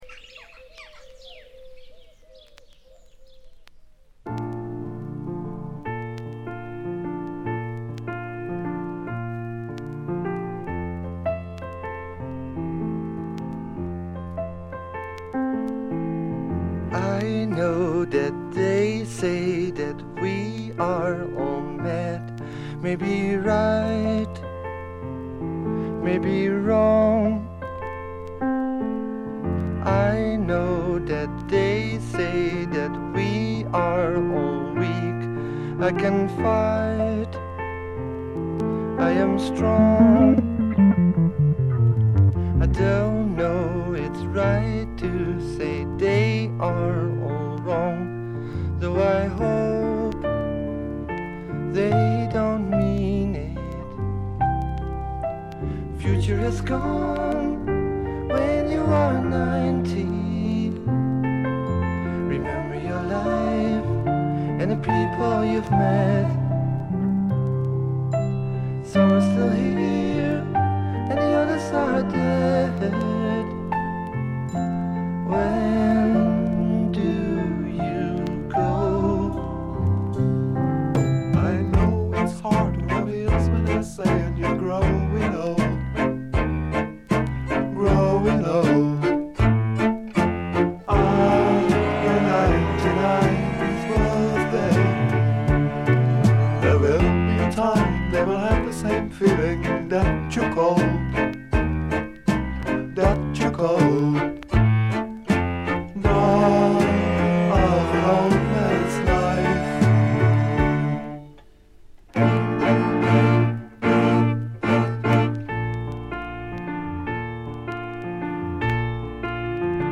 ざっと全編試聴しました。バックグラウンドノイズ、チリプチやや多め大きめ。
全体はチェンバー・ロック風な雰囲気ですが、フォークロックとか哀愁の英国ポップ風味が濃厚ですね。
試聴曲は現品からの取り込み音源です。